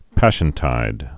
(păshən-tīd)